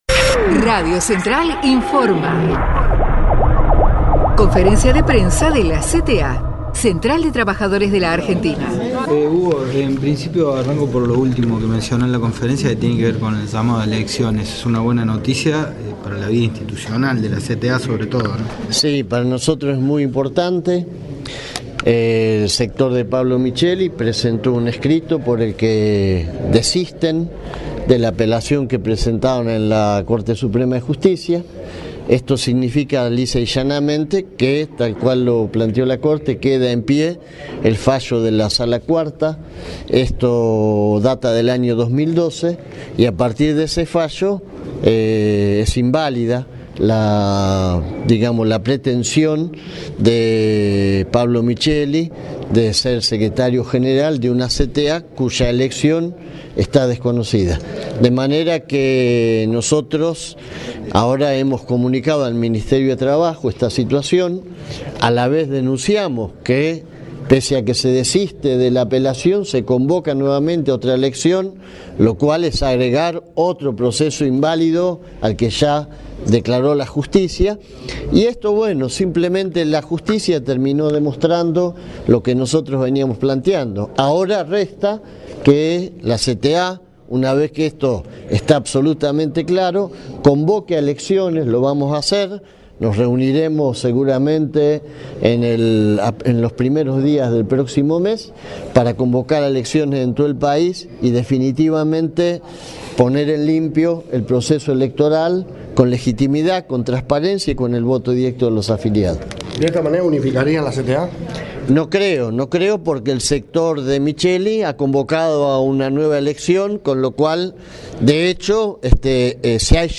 HUGO YASKY - rueda de prensa (13 de mayo)